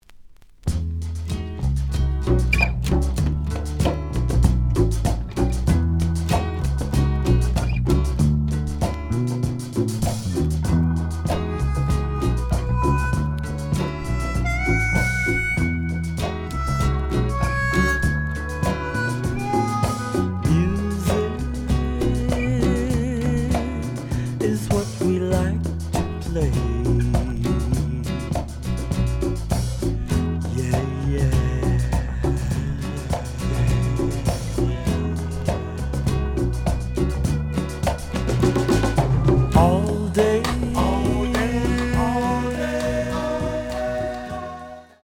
試聴は実際のレコードから録音しています。
The audio sample is recorded from the actual item.
●Genre: Funk, 70's Funk